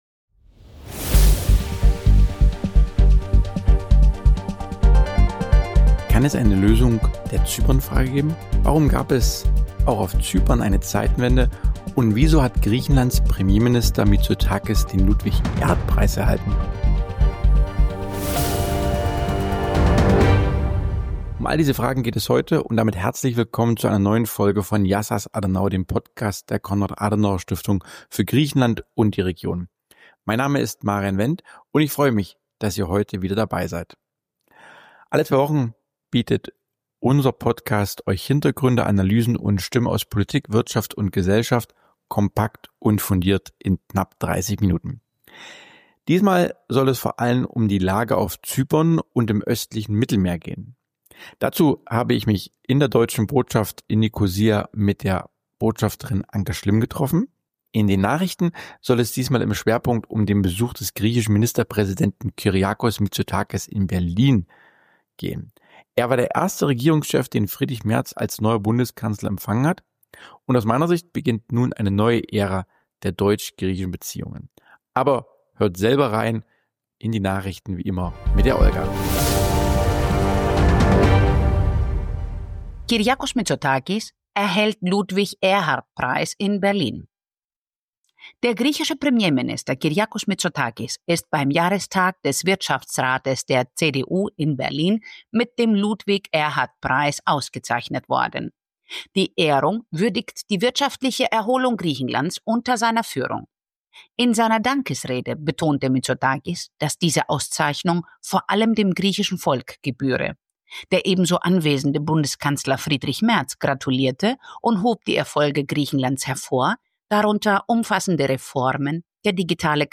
Beschreibung vor 10 Monaten In dieser Folge von Yiasas Adenauer ist Anke Schlimm, die scheidende Botschafterin der Bundesrepublik Deutschland in Zypern, zu Gast. Im Gespräch mit Marian Wendt blickt sie auf vier bewegte Jahre im diplomatischen Dienst zurück: Sie spricht über die ungelöste Zypern-Frage, eine geopolitische Zeitenwende durch den Krieg in der Ukraine, die strategische Rolle Zyperns im Nahostkonflikt sowie über die Vorbereitungen auf die EU-Ratspräsidentschaft 2026.